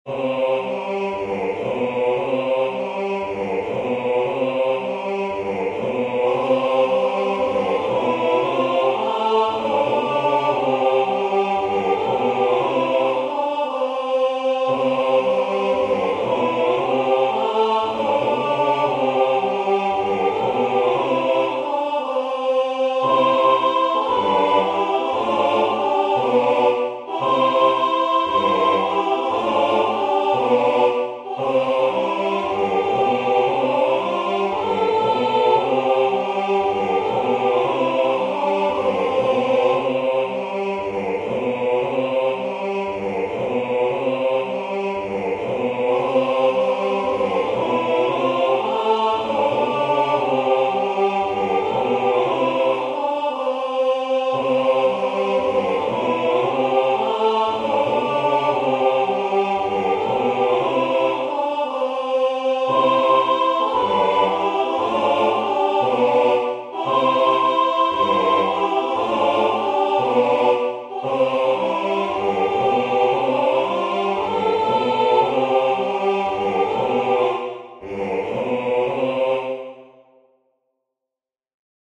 Voicing/Instrumentation: SAB
Children's Songs
A Cappella/Optional A Capella